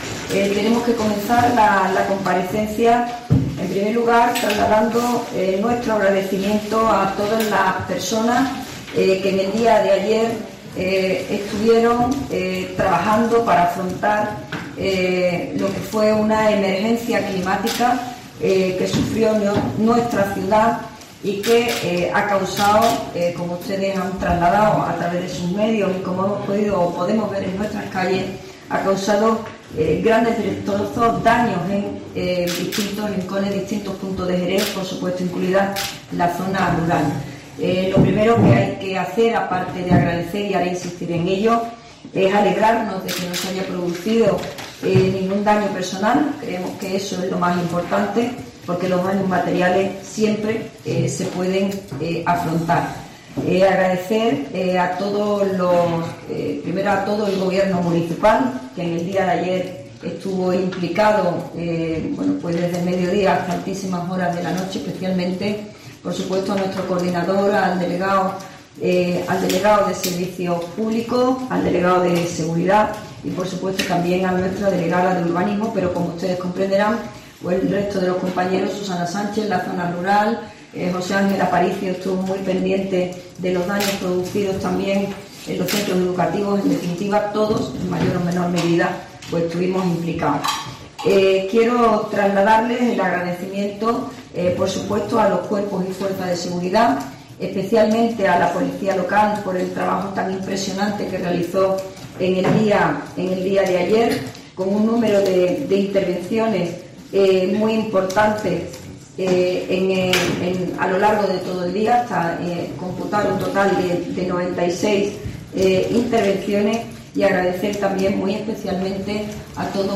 La alcaldesa de Jerez hace balance de la borrasca 'Bernard': 96 intervenciones de la Policía Local, 76 caídas de árboles, 16 daños en mobiliario urbano y 36 coches y tres colegios